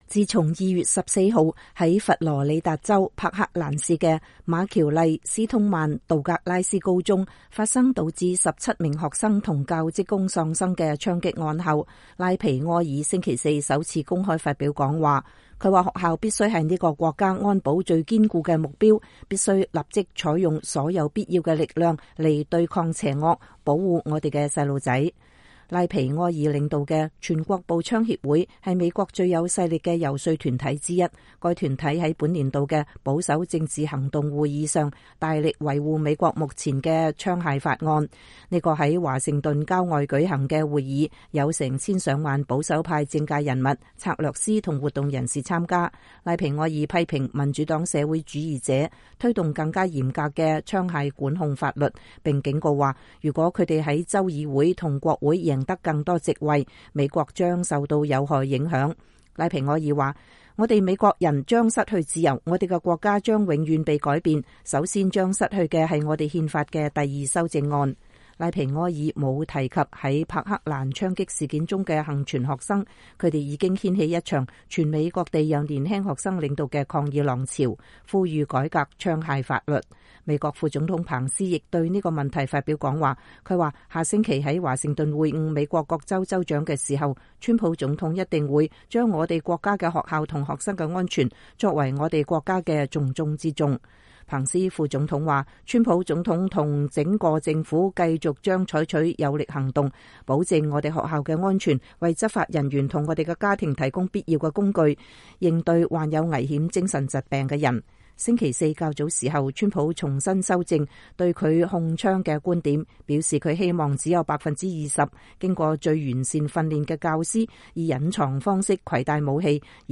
美國全國步槍協會副總裁兼首席執行官拉皮埃爾2月22日在馬里蘭州國家港口舉行的年度“保守政治行動會議”上發言